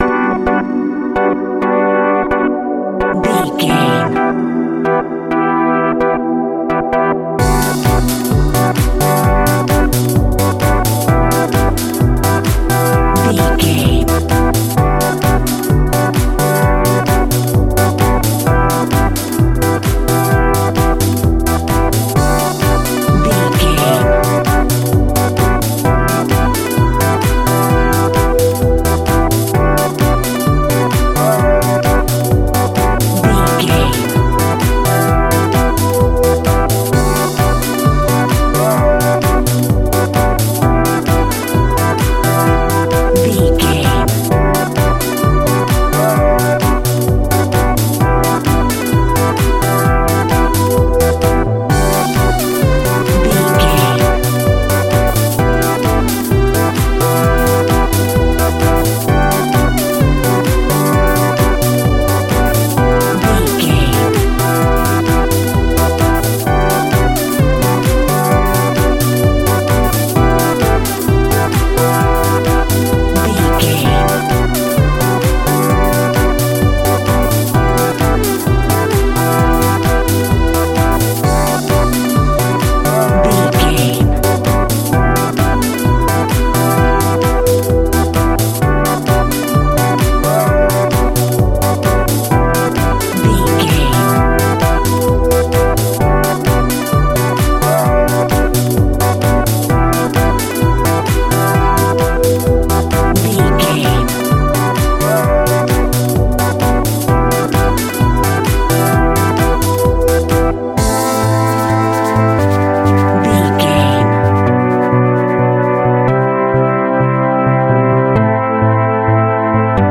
Funky House Electric Cue.
Aeolian/Minor
groovy
uplifting
driving
energetic
repetitive
synthesiser
drum machine
nu disco
upbeat
funky guitar
wah clavinet
synth bass
horns